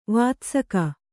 ♪ vātsaka